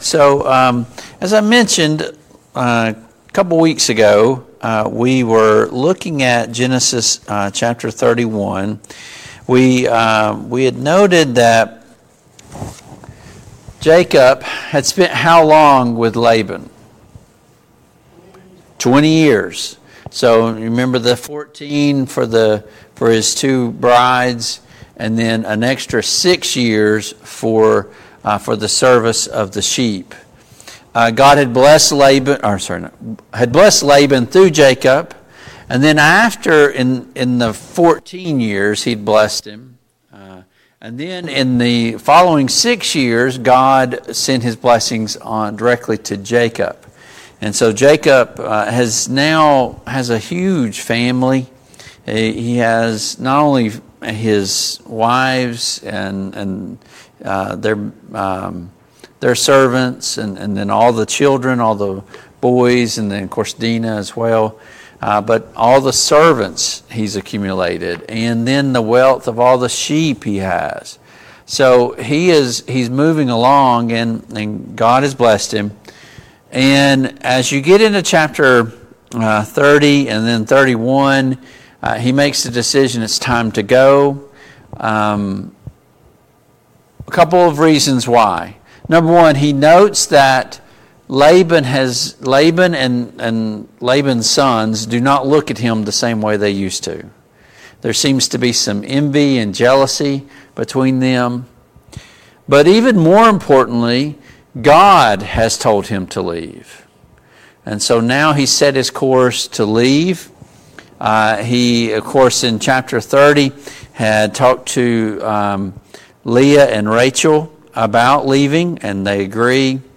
Service Type: Family Bible Hour Topics: Jacob and Laban